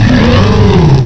cry_not_emboar.aif